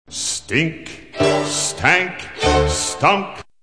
Empty Recycle Bin 1(25K)
grinch_empty_recycle_bin1.wav